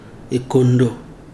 [LLL] noun world.